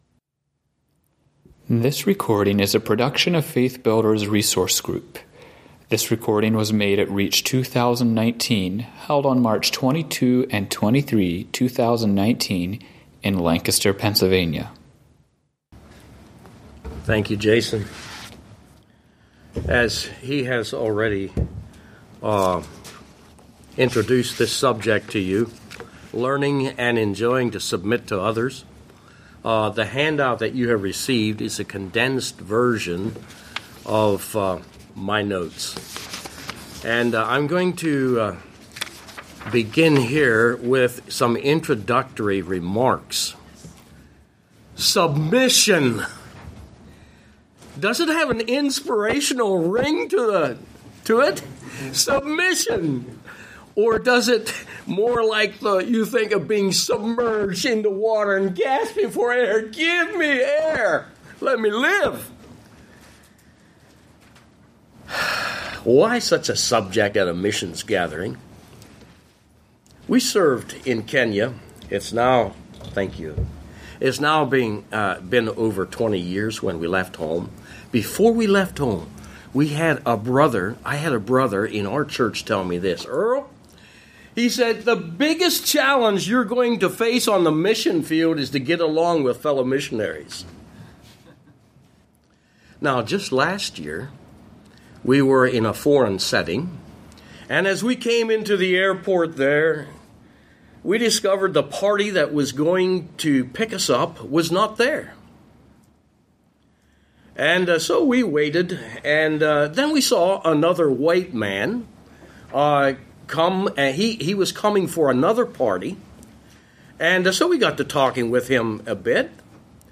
Home » Lectures » Learning (and Enjoying) to Submit to Others